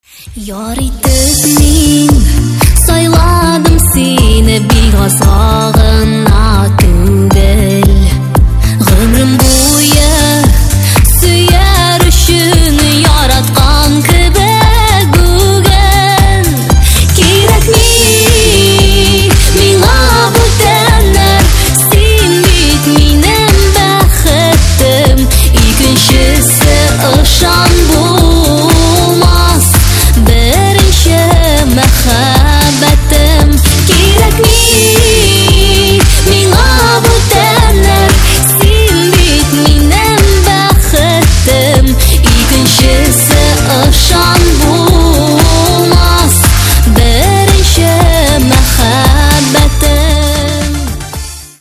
Kатегория: » Татарские рингтоны